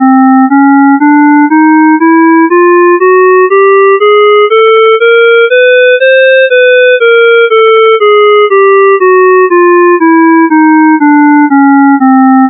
Let's now play the chromatic scale with our clarinet. We also add an envelope to get a more realistic sound. We can repeat every note as many times as we want; for example, the first note, C4, is played twice, at t = 0 and t = 12 sec.